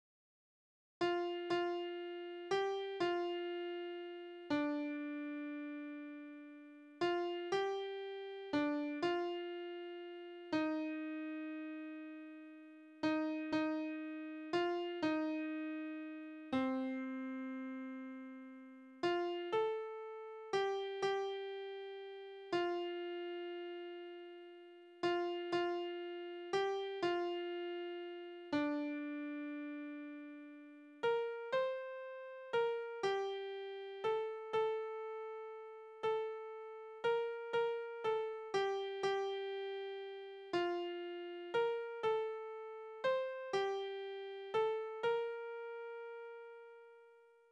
Liebeslieder: Der traurige Gärtner
Tonart: B-Dur
Taktart: 3/4
Tonumfang: Oktave